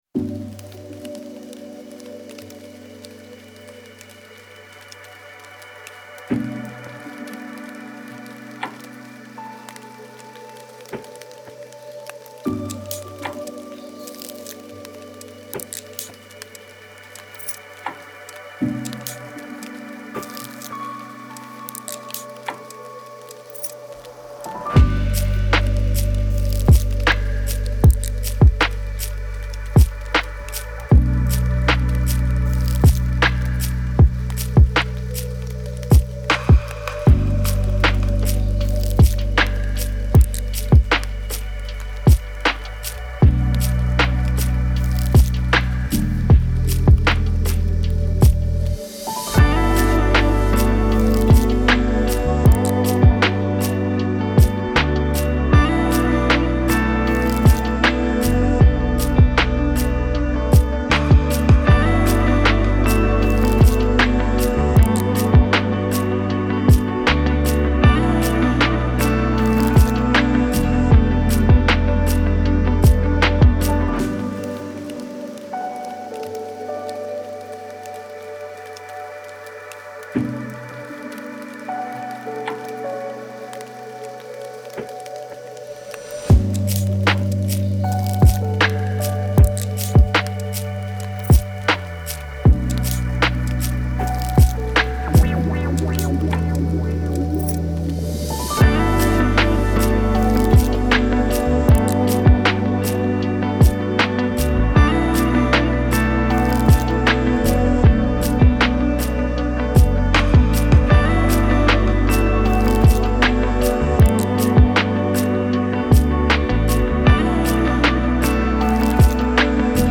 آرامش بخش تخیلی و رویایی موسیقی بی کلام
موسیقی بی کلام تخیلی موسیقی بی کلام رویایی